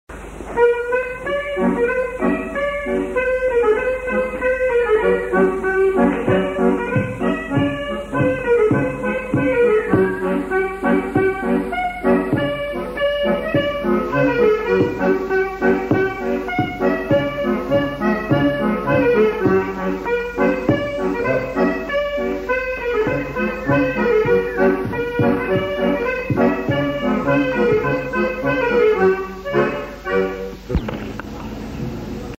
Scottish